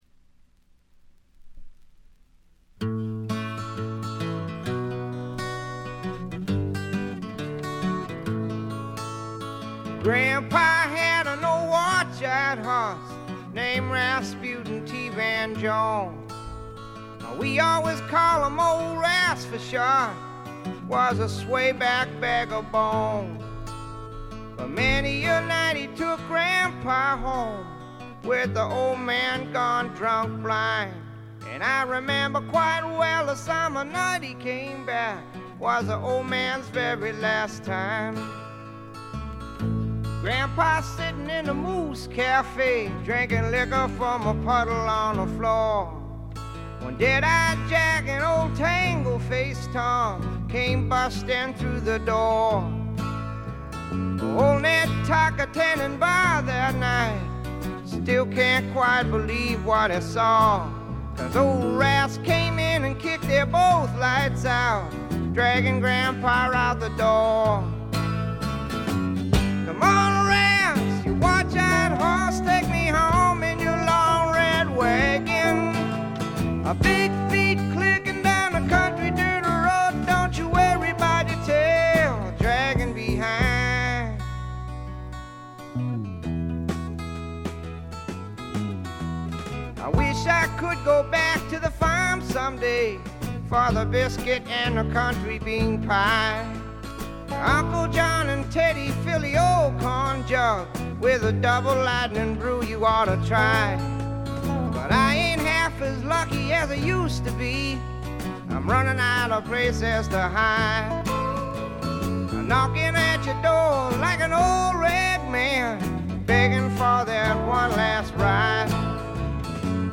ほとんどノイズ感無し。
試聴曲は現品からの取り込み音源です。
Vocals, Guitar, Harmonica